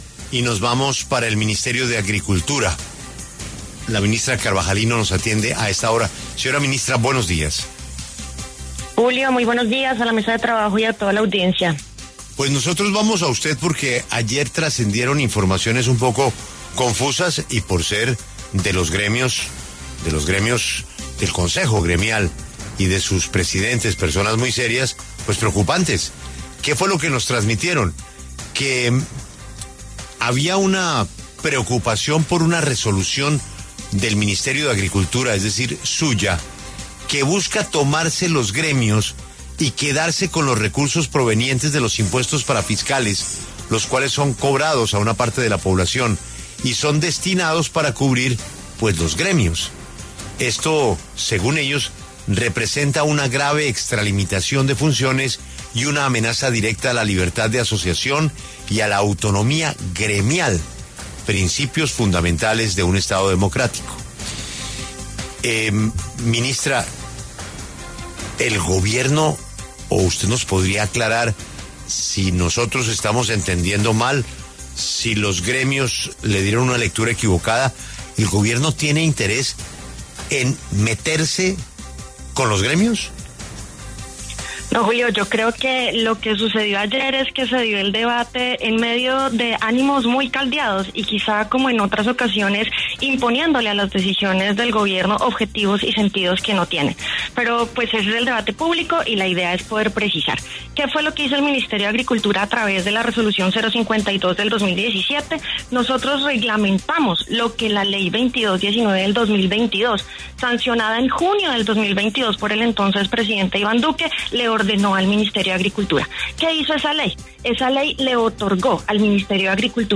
En entrevista con La W, la ministra de Agricultura, Martha Carvajalino, dio sus impresiones frente a las críticas que ha recibido desde diferentes sectores y gremios por su Resolución 052 de 2025, en la cual se expone que podrá vigilar, sancionar o hasta suspender las organizaciones gremiales agropecuarias y asociaciones campesinas nacionales que a visión de la cartera y lo estipulado en la ley, no cumplan o excedan los límites.